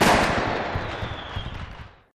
firework_explosion_01.ogg